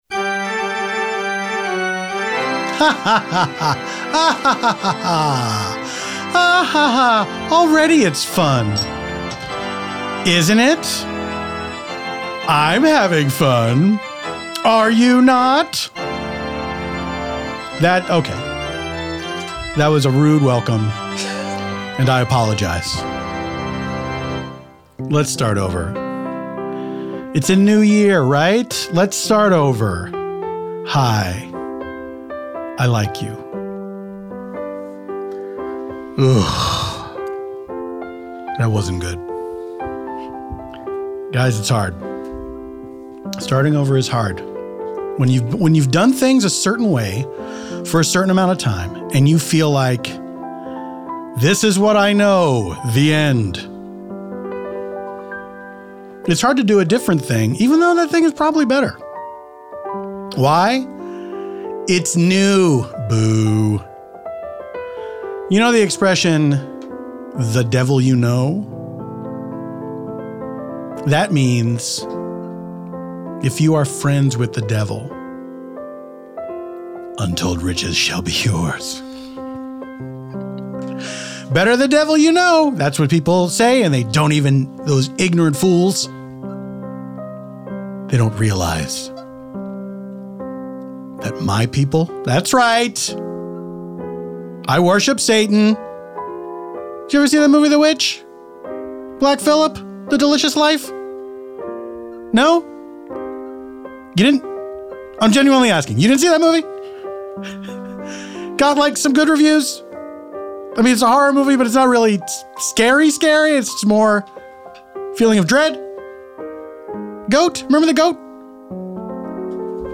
This time out, Paul's special return guest is hip-hop artist Open Mike Eagle!